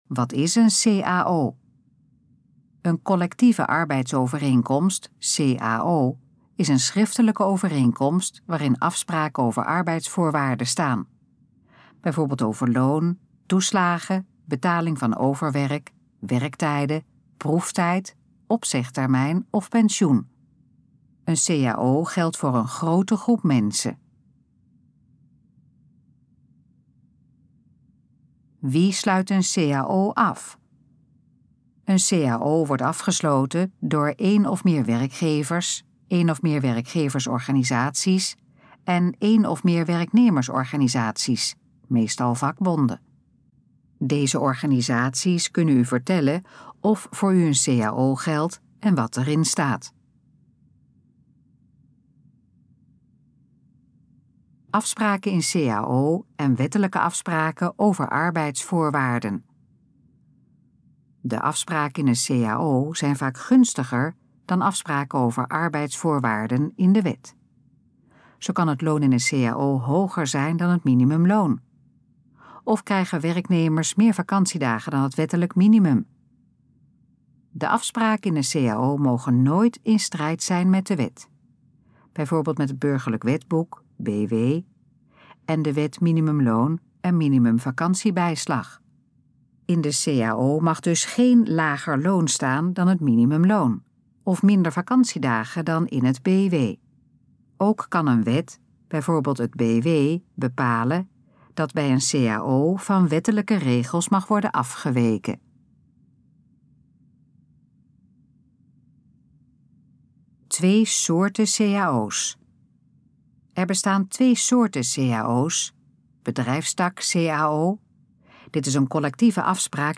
Gesproken versie van Wat is een cao?
Dit geluidsfragment is de gesproken versie van de informatie op de pagina Wat is een cao?